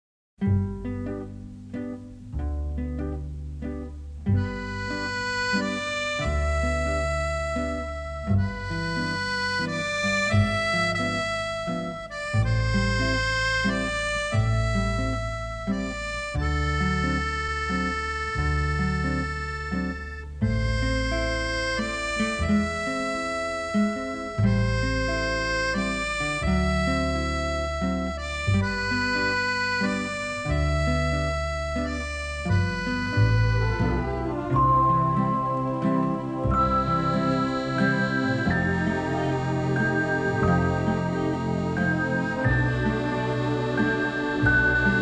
jazz and mood cues
shimmering with color, charm and melody